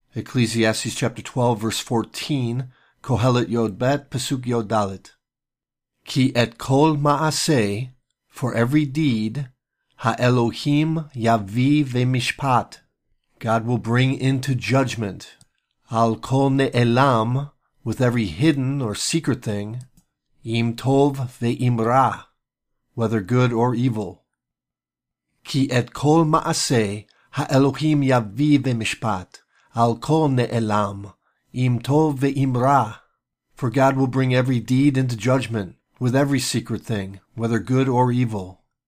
Hebrew Lesson
Ecclesiates 12:14 reading (click for audio):